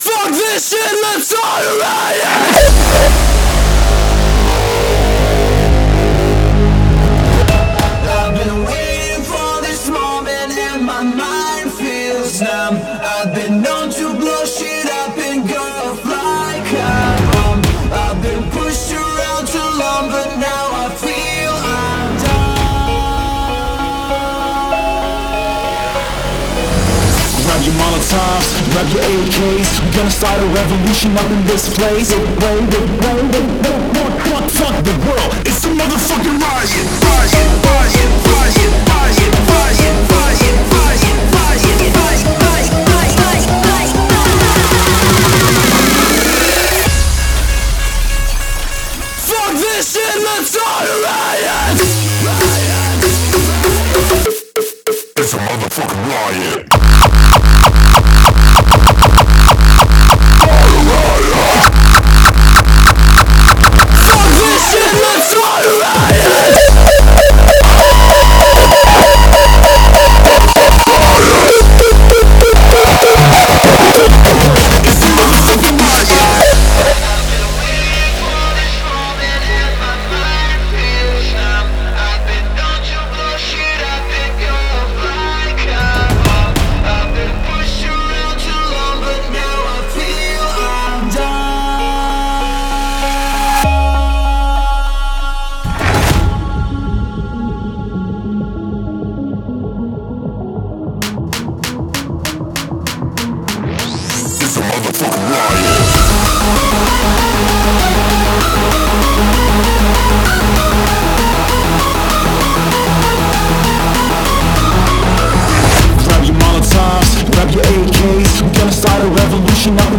• Категория:Hardstyle